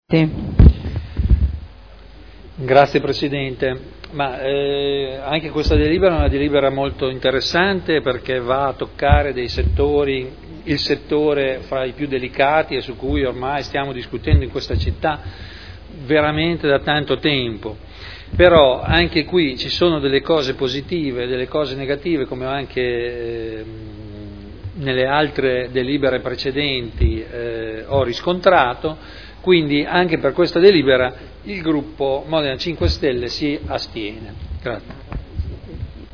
Dichiarazione di voto.